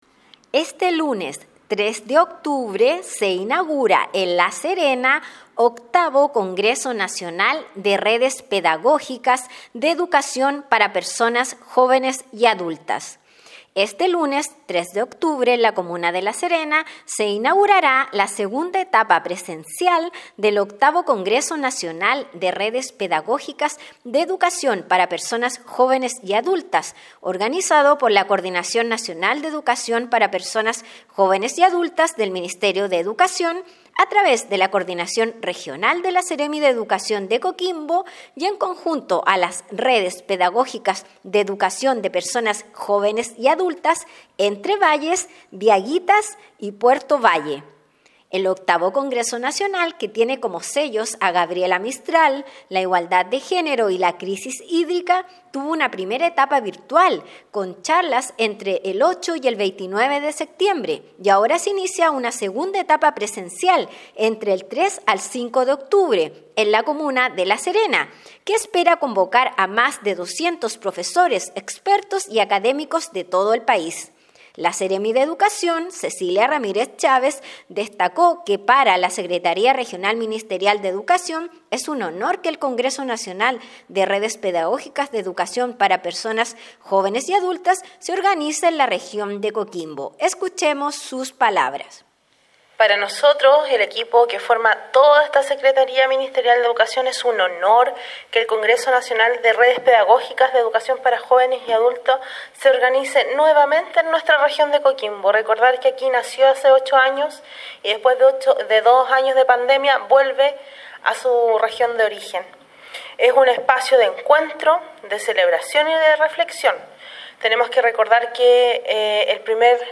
AUDIO : Despacho Radial Este lunes 03 de octubre se inaugura 8° Congreso Nacional de Redes Pedagógicas de Educación para Personas Jóvenes y Adultas